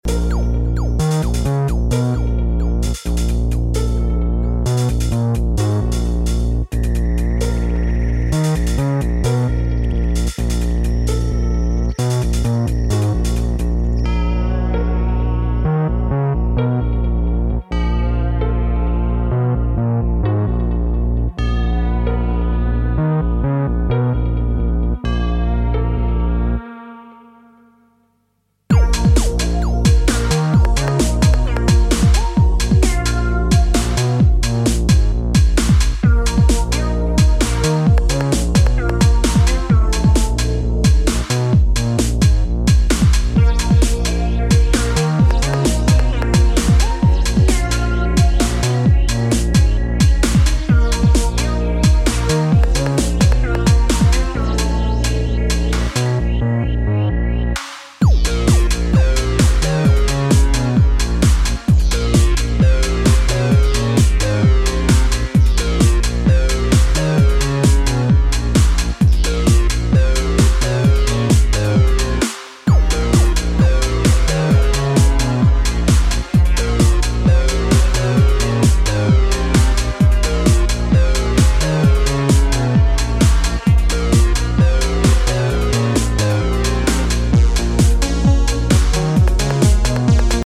have a solid techno imprint